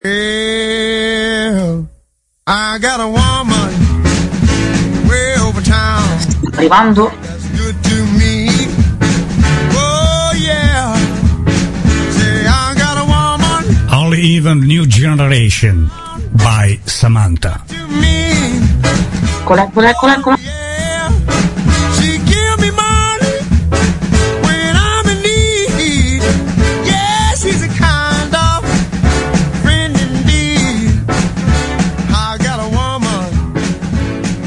Quartiere Coffee Intervista.mp3